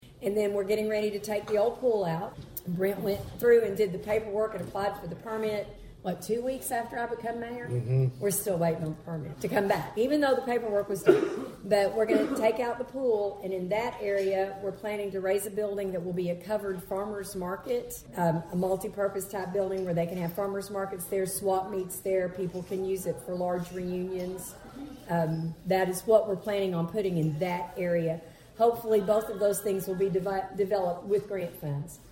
Thayer Mayor Donna Martin speaks at Rotary on Wednesday